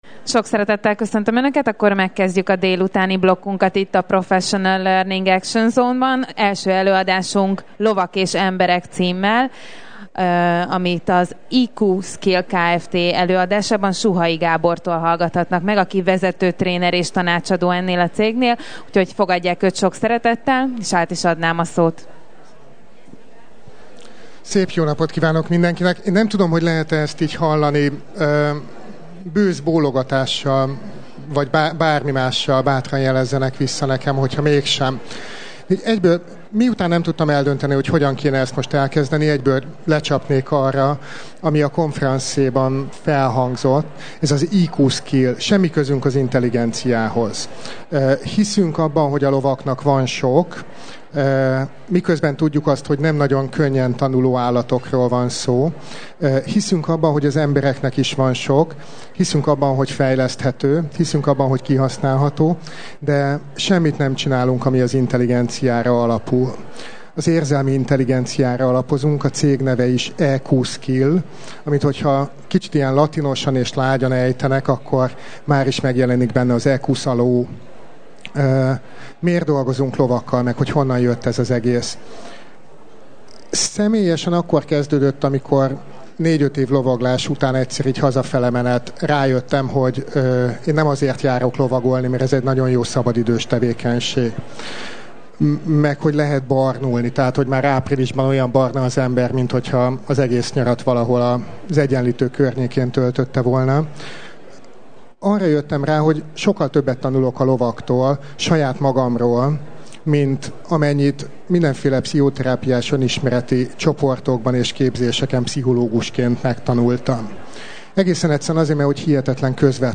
Lovak és emberek . Előadás a Personal Hungary 2010 Emberierőforrás-menedzsment Szakkiállításon.